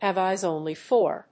アクセントhàve éyes ónly for…